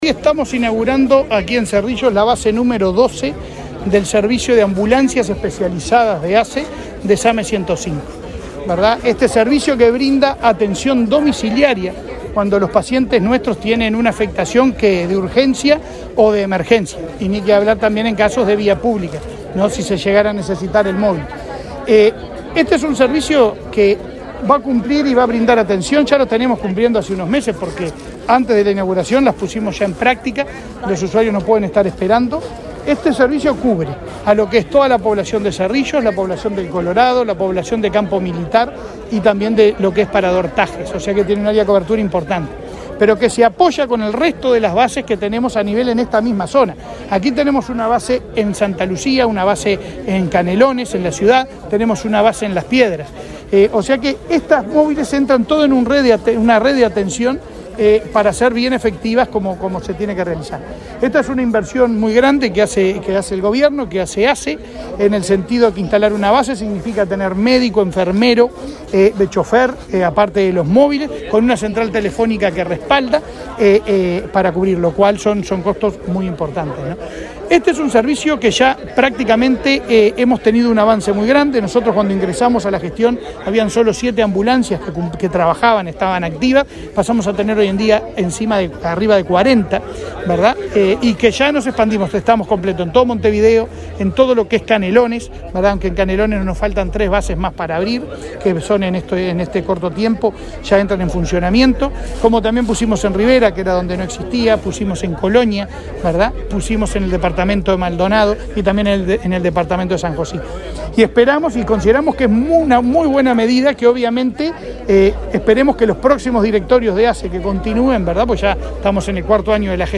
Entrevista al presidente de ASSE, Leonardo Cipriani
El presidente de la Administración de los Servicios de Salud del Estado (ASSE), Leonardo Cipriani, dialogó con Comunicación Presidencial, luego de